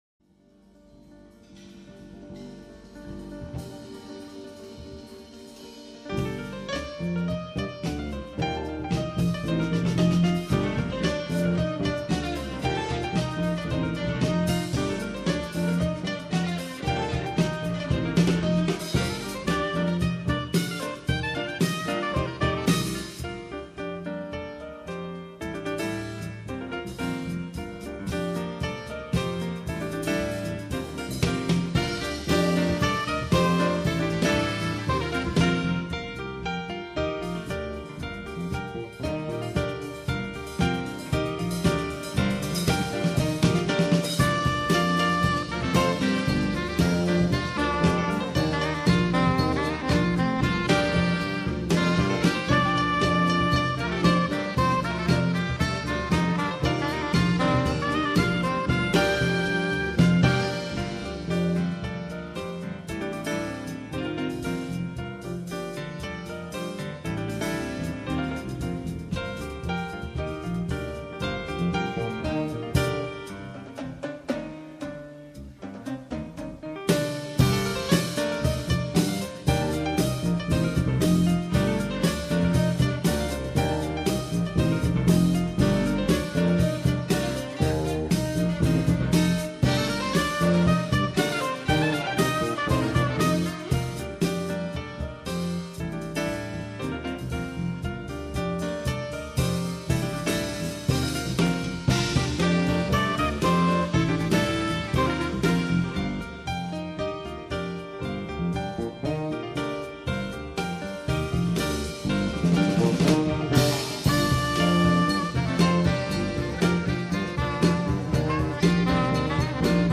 ΔΕΥΤΕΡΟ ΠΡΟΓΡΑΜΜΑ Ροκ Συναναστροφες Αφιερώματα Επετειακά Μουσική Συνεντεύξεις Εξι δεκαετιες ηλεκτρικο ελληνικο τραγουδι Ηεκτρικο Ελληνικο Τραγουδι Κυριακος Σφετσας Κυριακος Σφετσας αφιερωμα